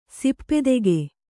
♪ dippedege